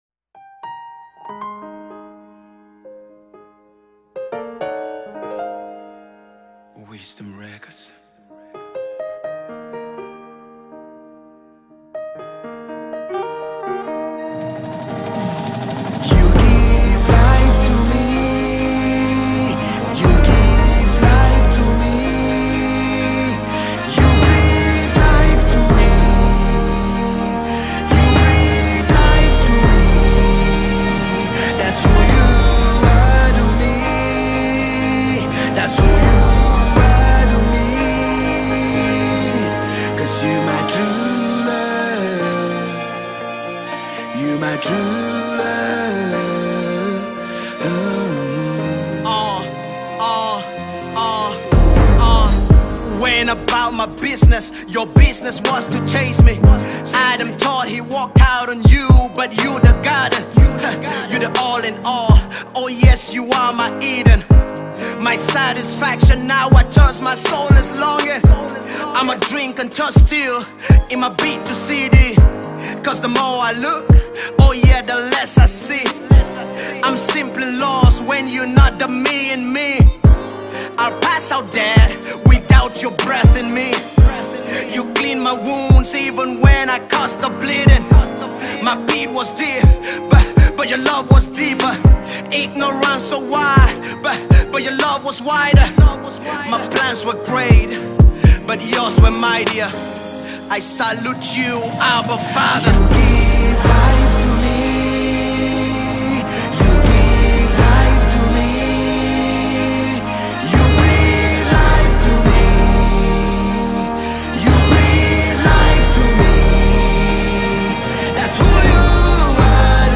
Cameroonian christian rapper-singer